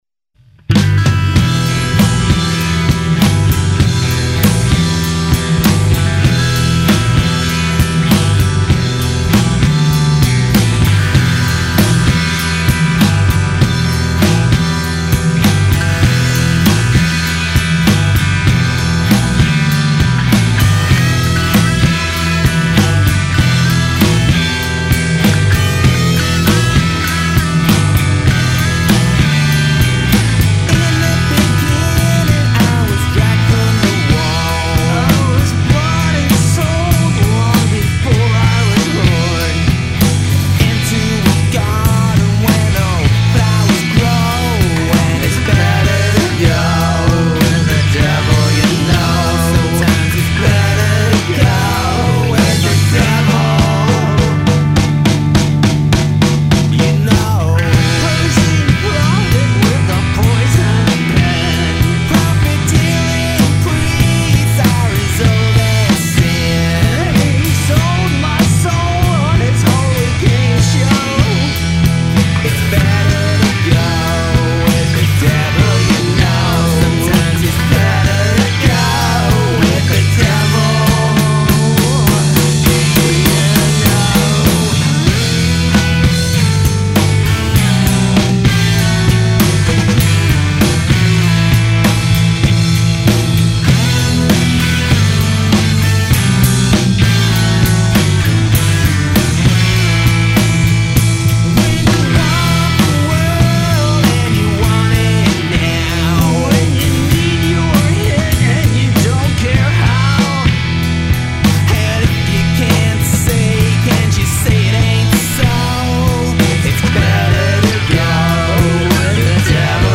Guitar
Bass
Vocals
Drums